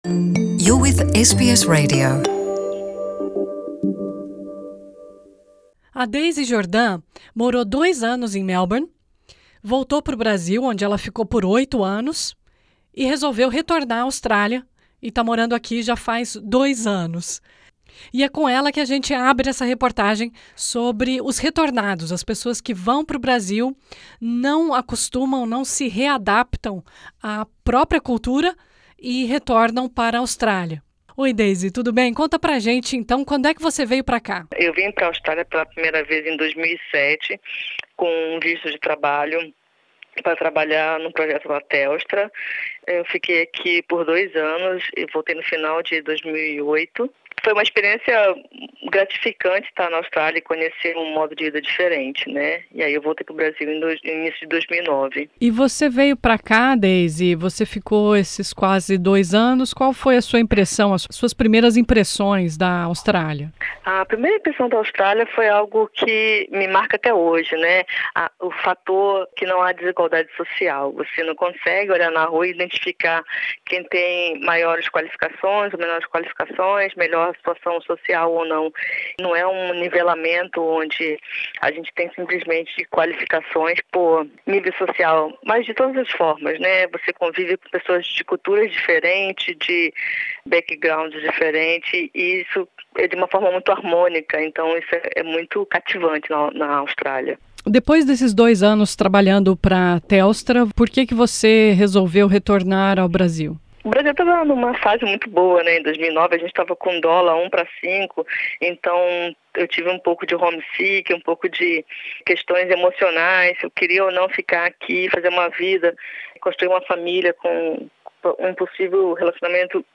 Conversamos com brasileiros que vieram morar na Austrália, não se adaptaram, voltaram ao Brasil e, após alguns anos, resolveram voltar para a Austrália.